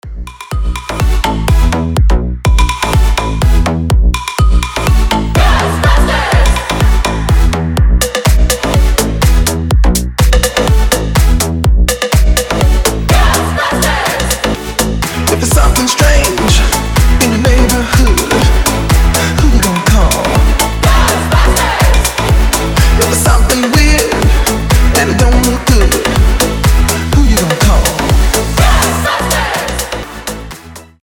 • Качество: 320, Stereo
retromix
веселые
Club House
энергичные
ремиксы
slap house